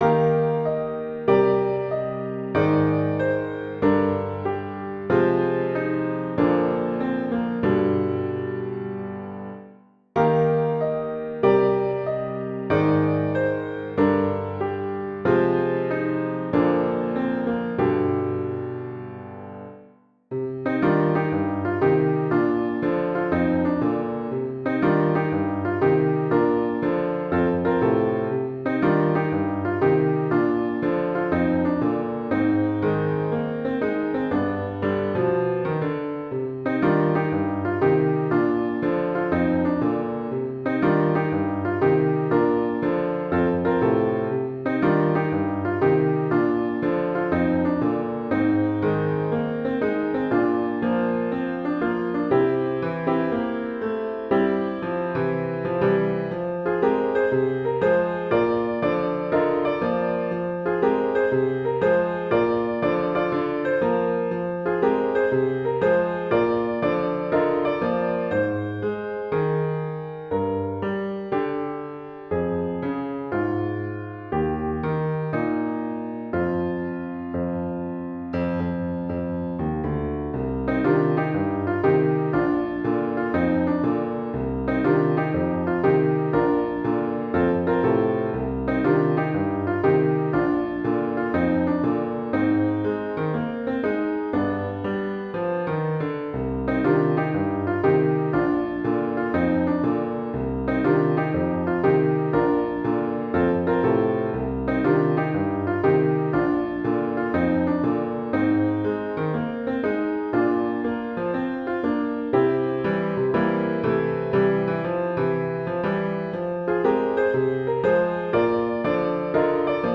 They are each recorded at performance speed.